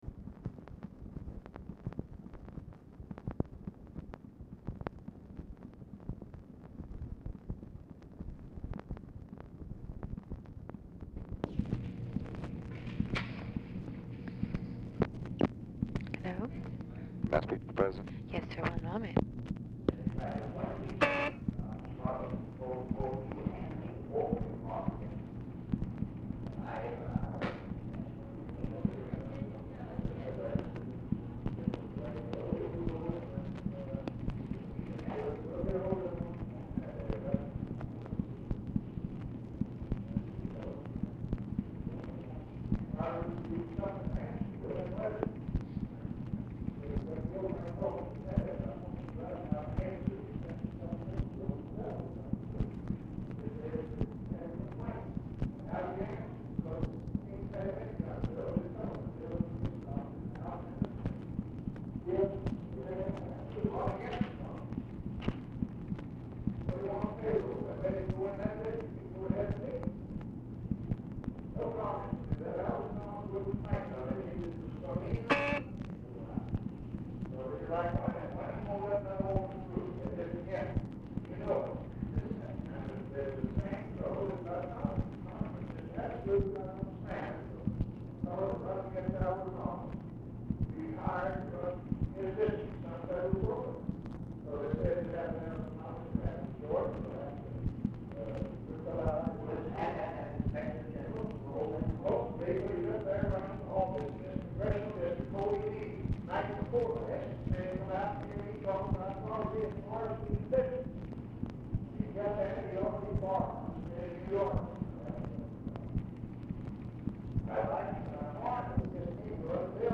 Telephone conversation # 7785, sound recording, LBJ and DEAN RUSK, 5/19/1965, 7:22PM
RUSK ON HOLD 2:00; OFFICE CONVERSATION PRECEDES CALL
Format Dictation belt